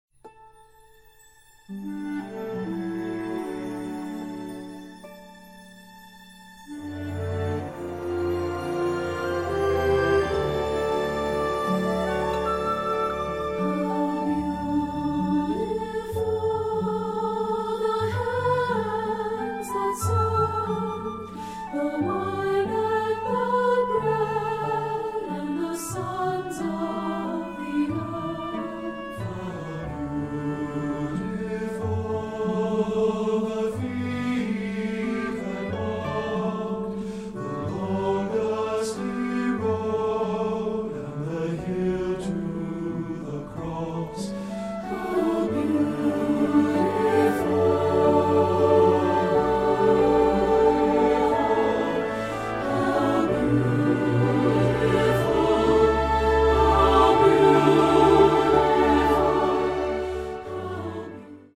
Voicing: Full Orch,